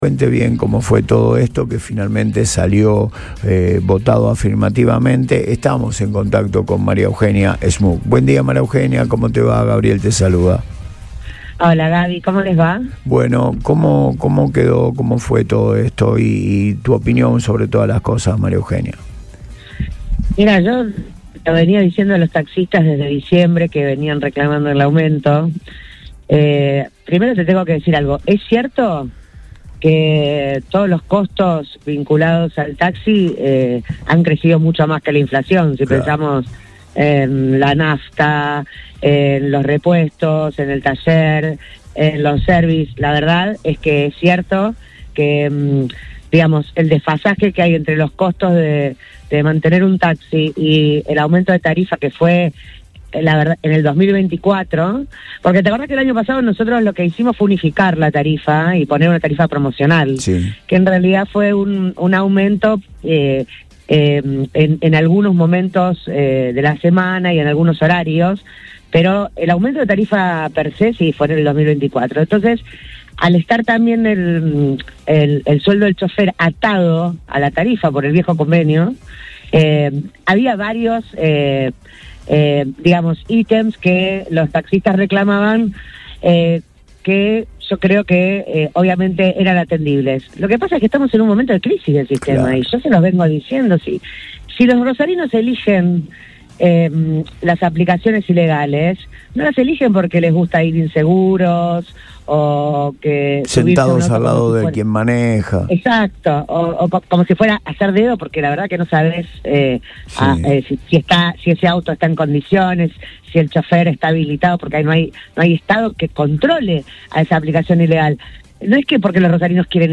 La presidenta del Concejo Municipal, María Eugenia Schmuck, pasó por los micrófonos de Radio Boing y analizó la compleja situación del transporte en la ciudad tras la aprobación de una suba del 30% en las tarifas.